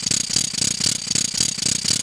Goofy Sound Effects
Ratchet Loop
RatchetLoop.WAV